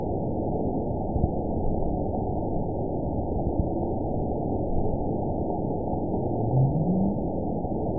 event 920338 date 03/17/24 time 03:13:48 GMT (1 year, 8 months ago) score 9.64 location TSS-AB03 detected by nrw target species NRW annotations +NRW Spectrogram: Frequency (kHz) vs. Time (s) audio not available .wav